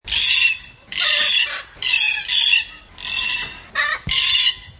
Cacatúa Alba (Cacatua alba)